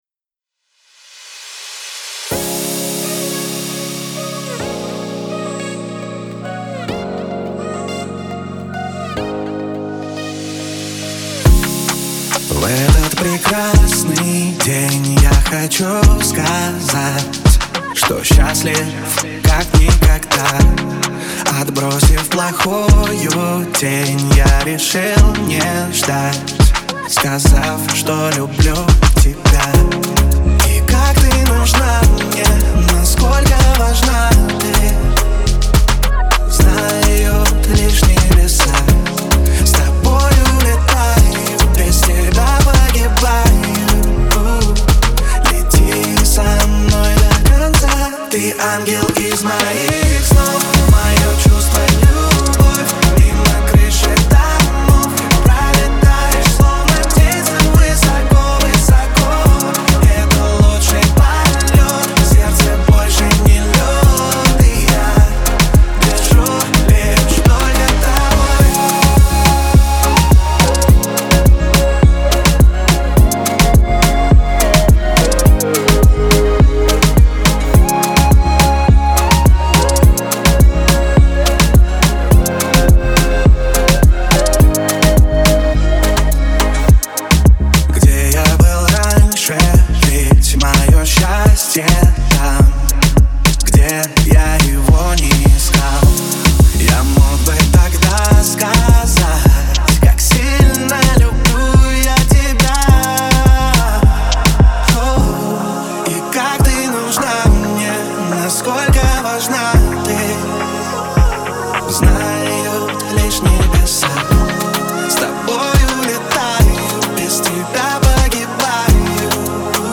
Трек размещён в разделе Русские песни / Саундтреки.